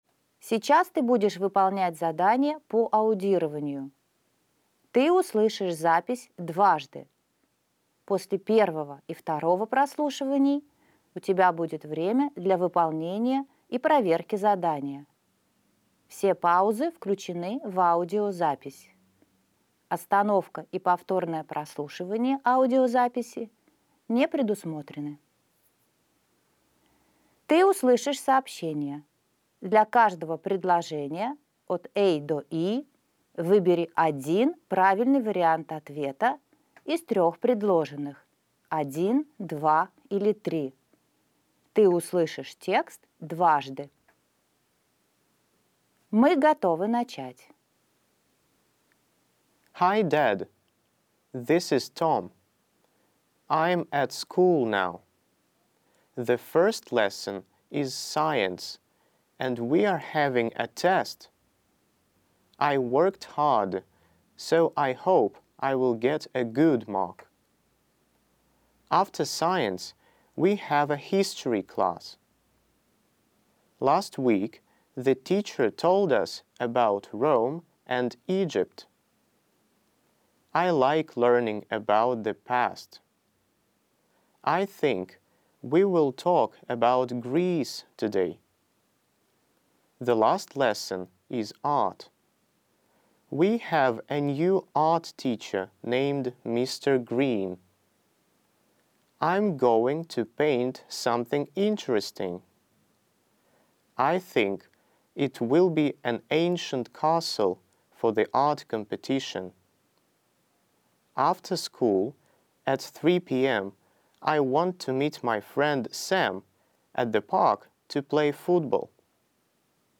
Ты услышишь текст дважды.